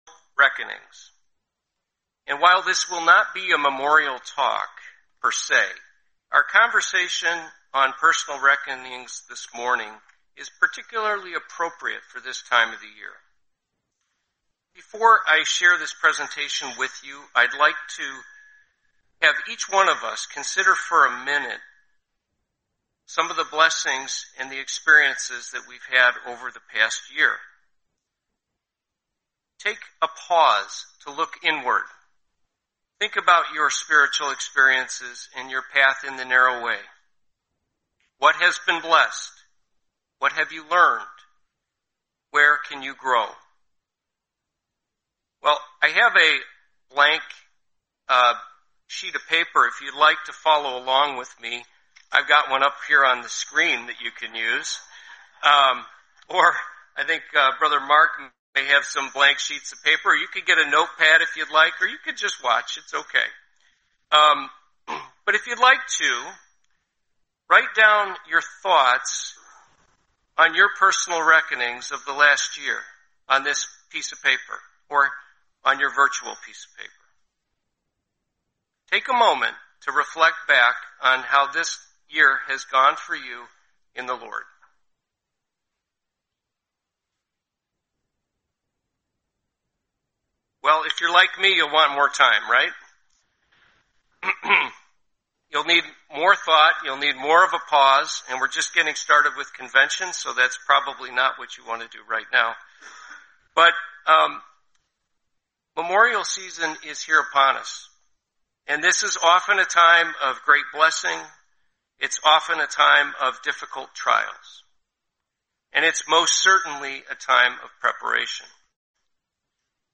Series: 2026 Florida Convention